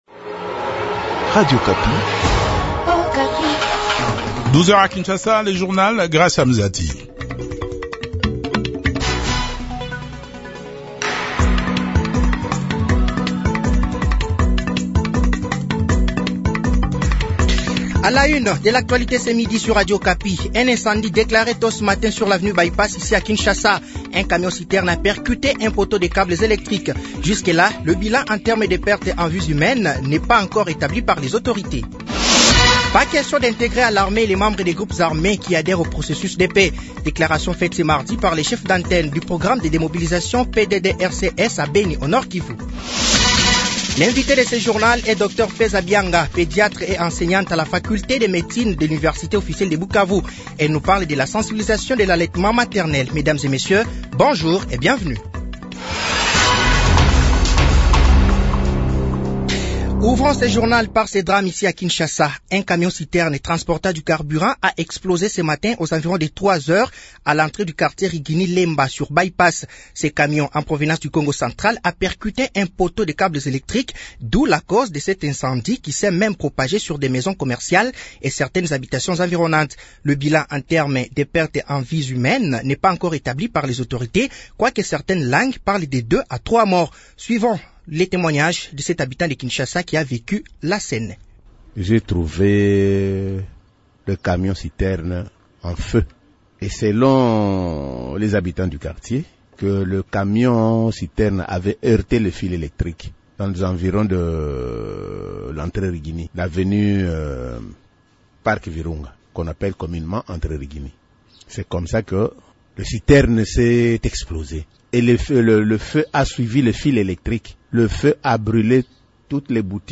Journal français de 12h de ce mardi 08 août 2023